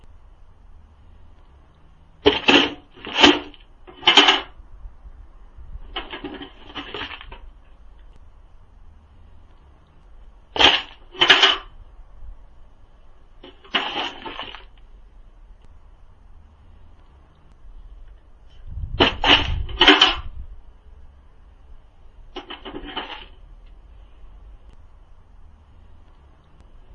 研磨咖啡豆
描述：用手磨机研磨咖啡豆，可能适用于任何类型的打磨声。
标签： 磨碎 金属 咖啡 碎石
声道单声道